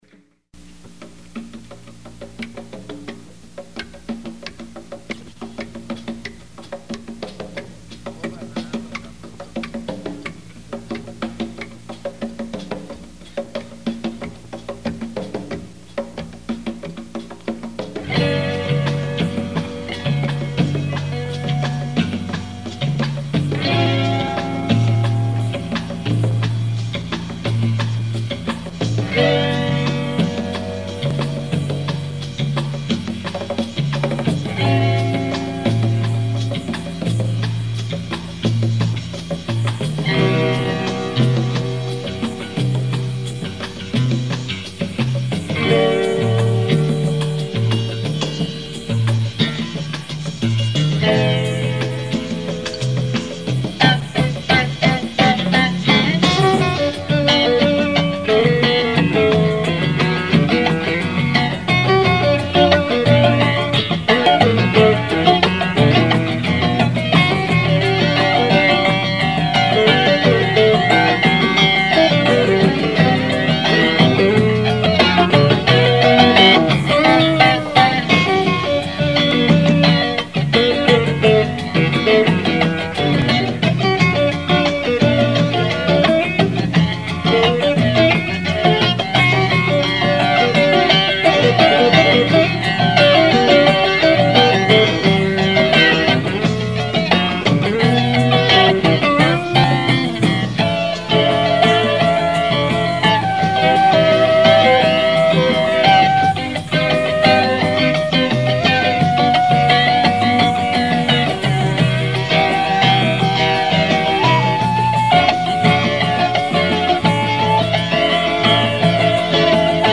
976   09:22:00   Faixa:     Rock Nacional